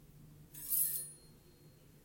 福里诊所 " 18 Placa consultorio
描述：Foley练习亮度
Tag: 亮度 实践 弗利